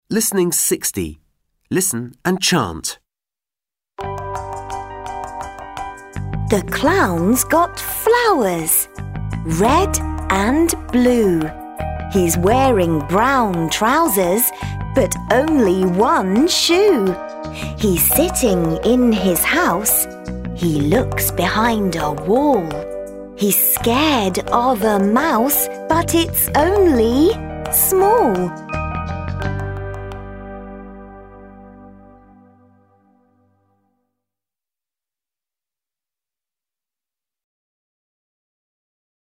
1) Listen, point and repeat.